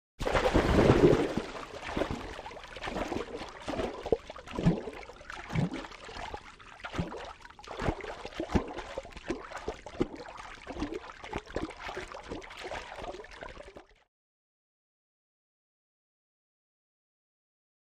Swimming 1; Under Water.